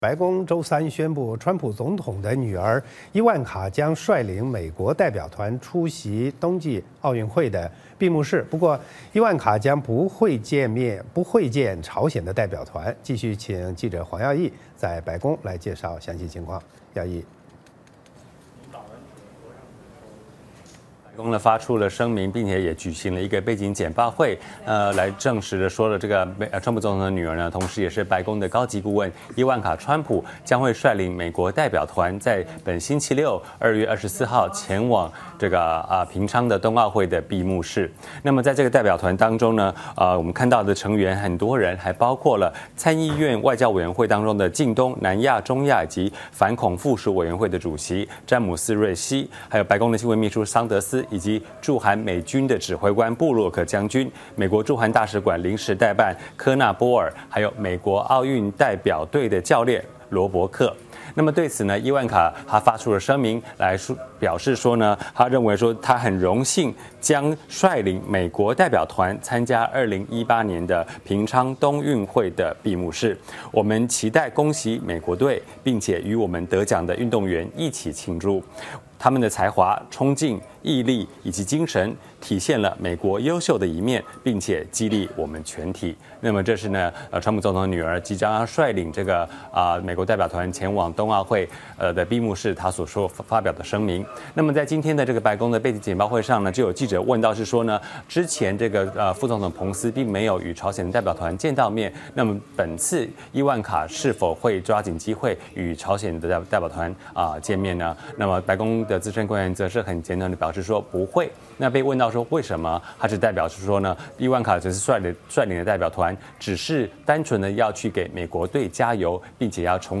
白宫 —